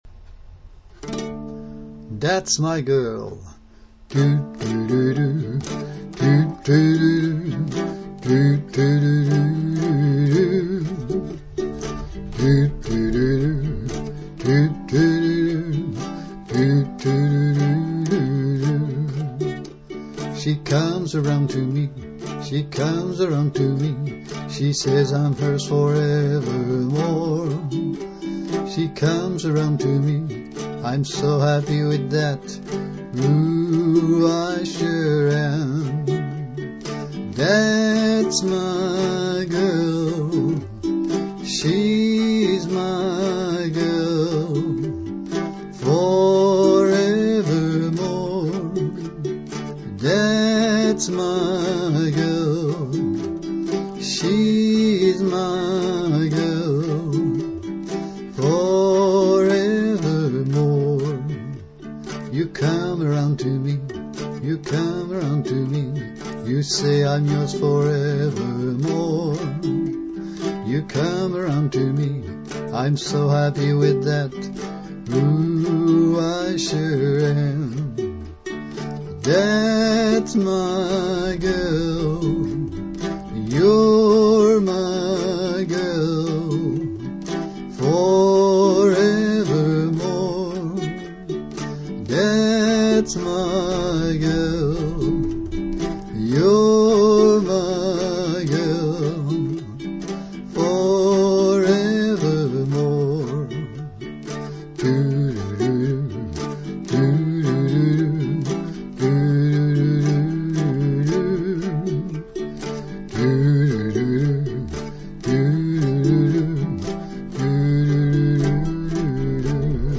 thatsmygirluke.mp3
key of D